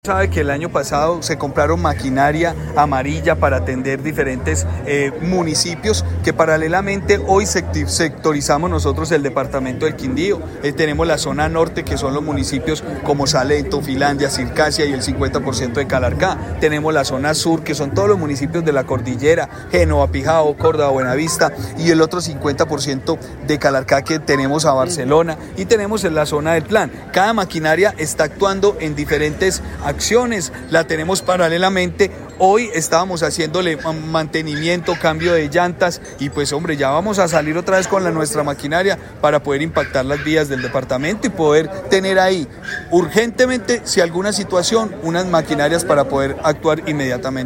Gobernador del Quindío sobre lluvias